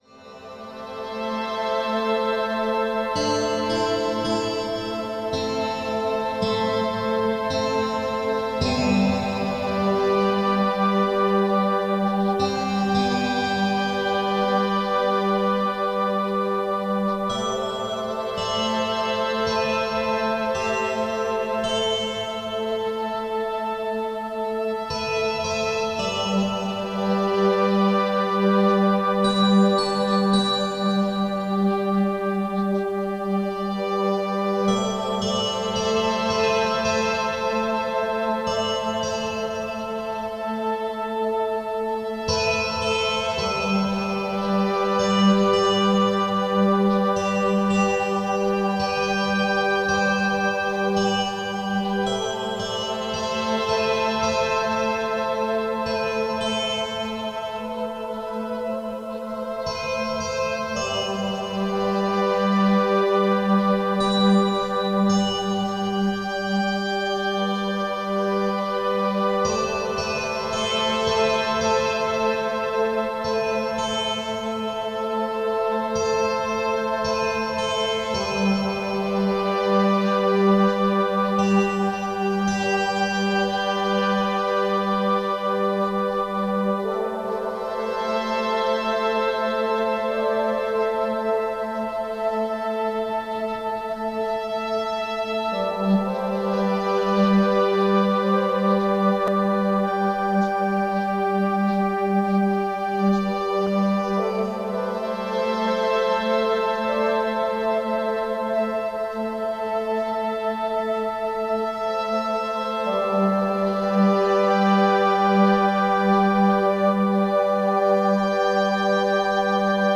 Das Lifeness Harmony Selbsthilfeprogramm: Die Macht des positiven Denkens - Hörbuch